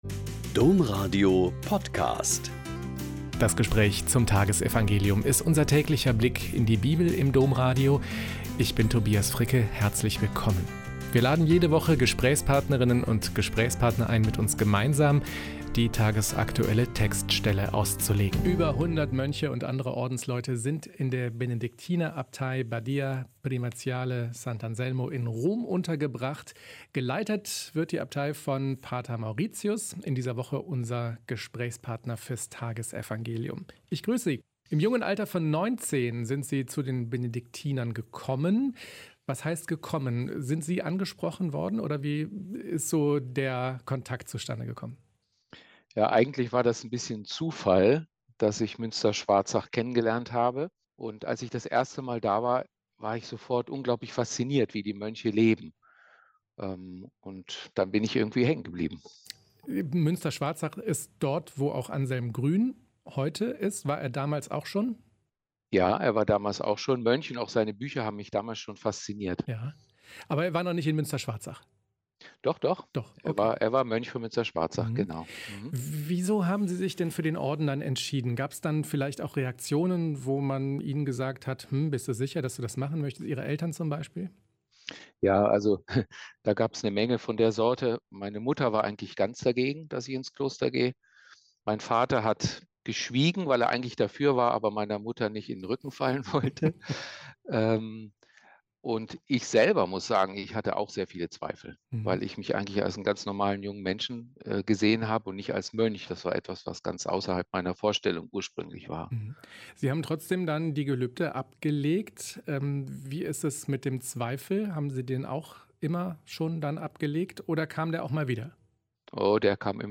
Lk 19,1-10 - Gespräch